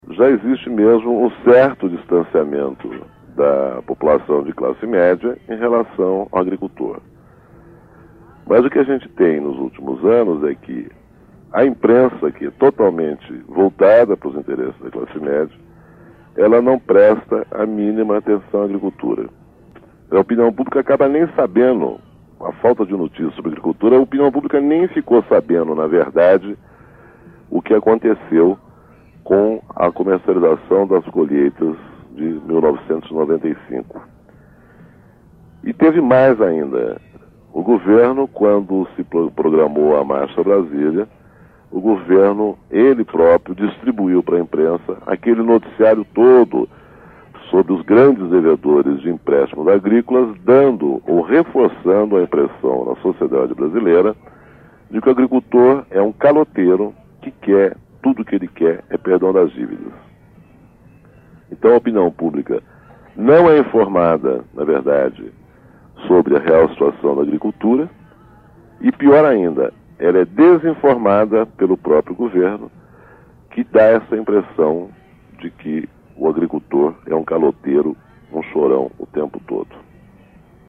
Em mais um trecho da carta falada que o jornalista gravou para o programa A Voz da Contag, a crítica foi para o impacto para os produtores diante (...)